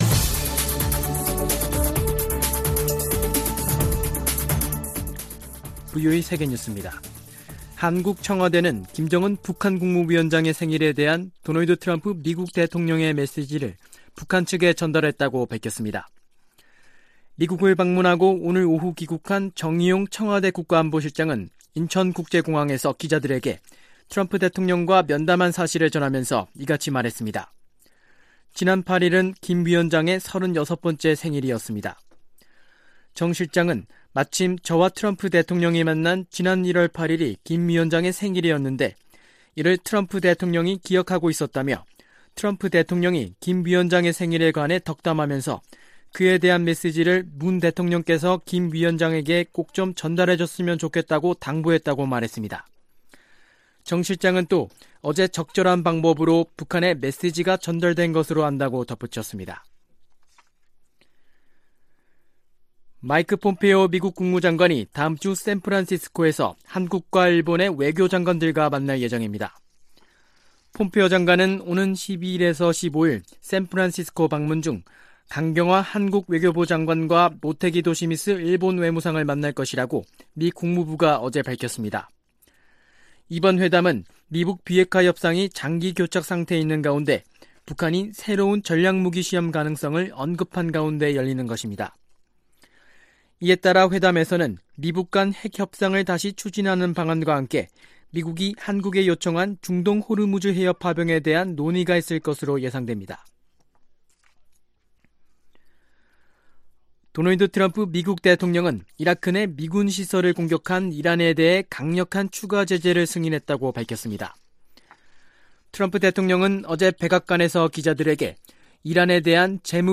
VOA 한국어 간판 뉴스 프로그램 '뉴스 투데이', 2020년 1월 10일 3부 방송입니다. 트럼프 미국 대통령은 문재인 한국 대통령을 통해 김정은 북한 국무위원장에게 생일 메시지를 전달했습니다. 미국의 여론조사 전문기관이 실시한 국제적인 여론조사에서 트럼프 행정부의 외교정책은 매우 부정적이었으나 미-북 협상에 대해서는 긍정적 평가가 많았습니다.